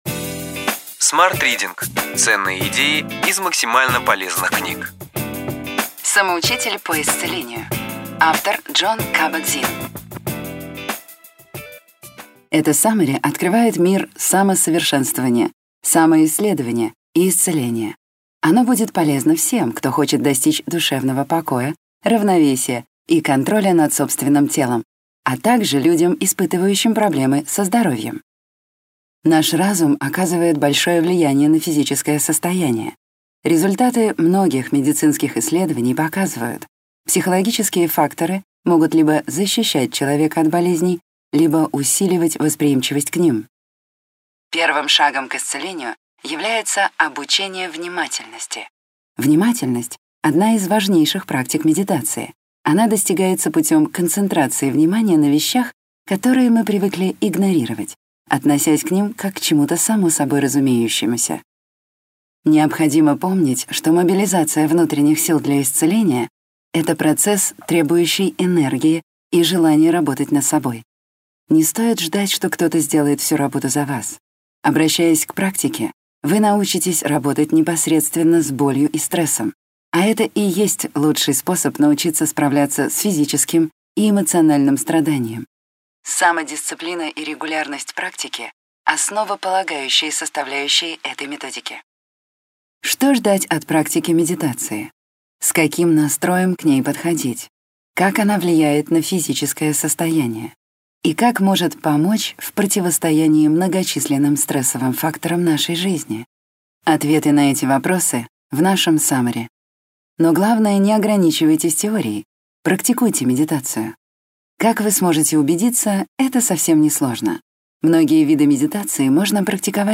Аудиокнига Ключевые идеи книги: Самоучитель по исцелению.